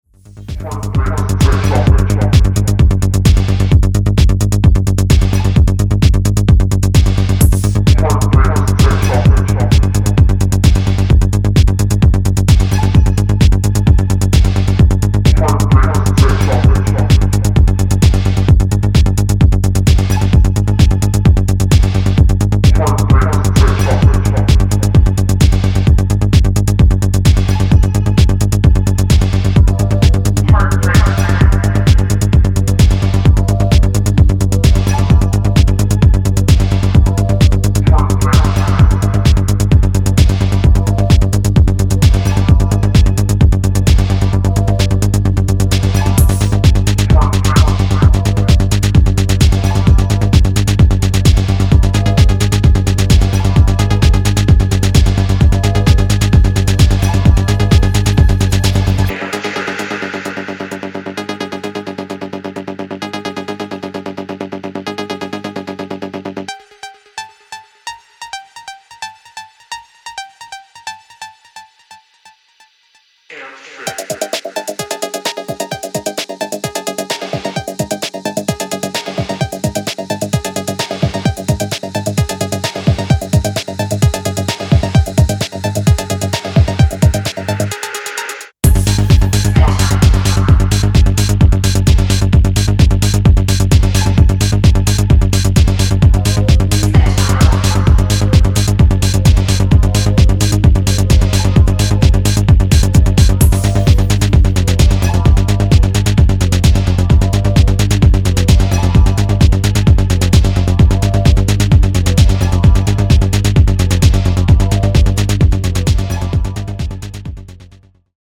シンセベースのアルペジオとダークな加工ヴォイスで真っ暗なフロアを突き進む会心のテック・ハウス